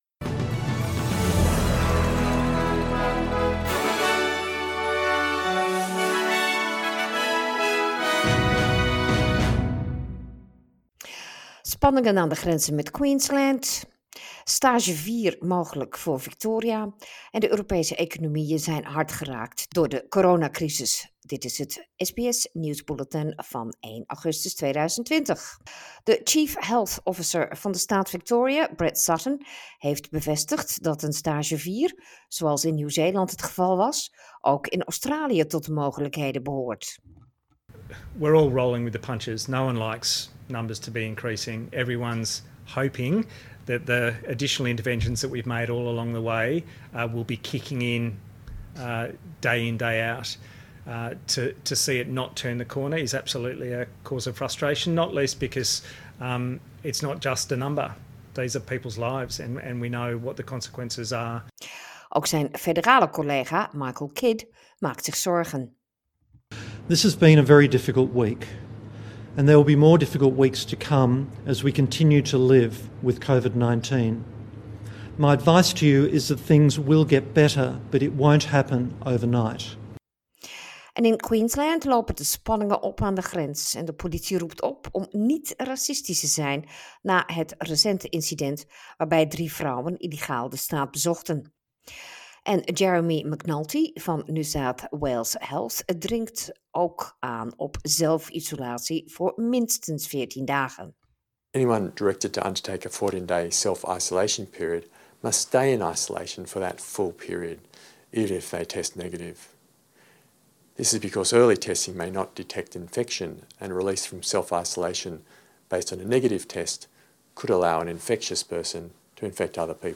Nederlands/Australisch SBS Dutch nieuwsbulletin 1/8/2020
dutch_news_1_aug.mp3